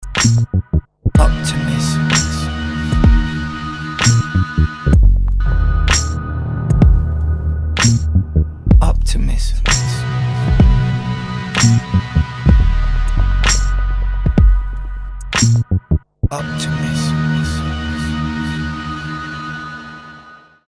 Trap
депрессивные
мрачные